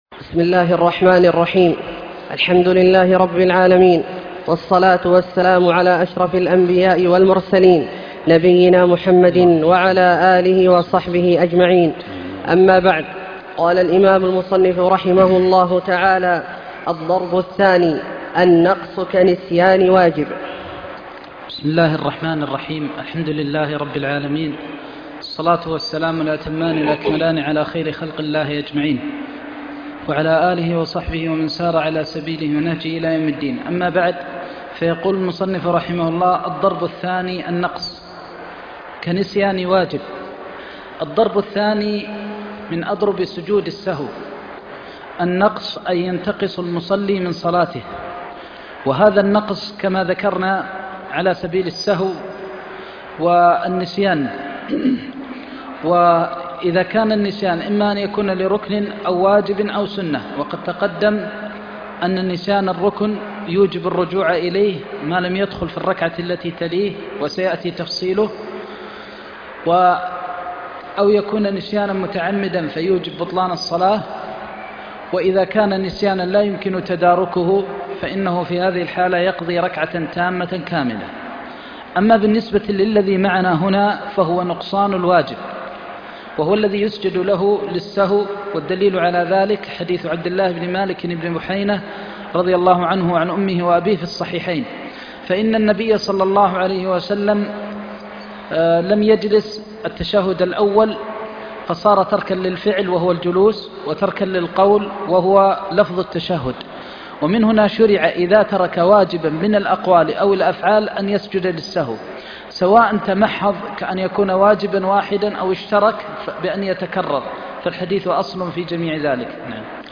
درس عمدة الفقه (12) - تابع كتاب الصلاة